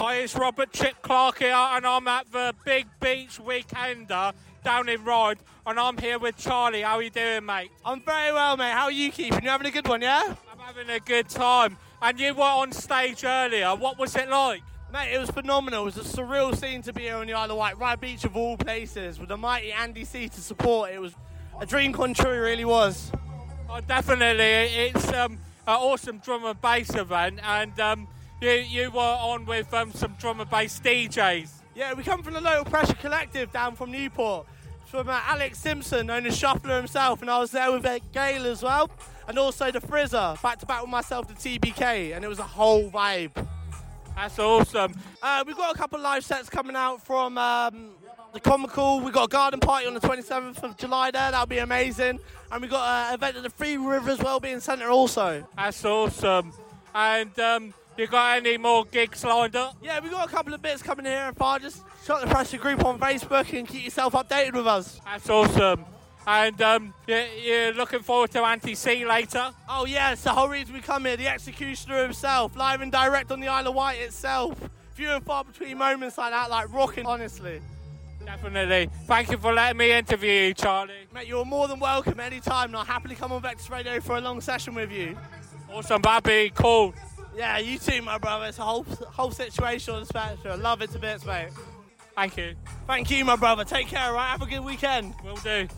Interview Big Beach Weekender Interview 2024